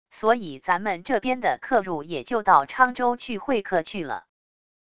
所以咱们这边的客入也就到昌州去会客去了wav音频生成系统WAV Audio Player